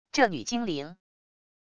这女精灵wav音频